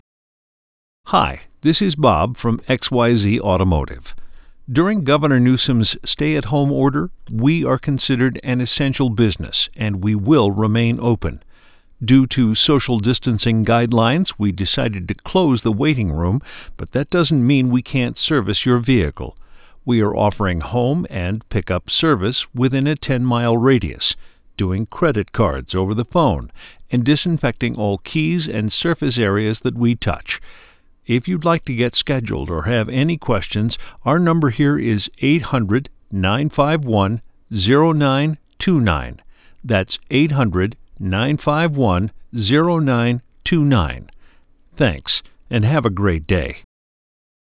Ringless Voicemail
Covid19 Voice Mail.wav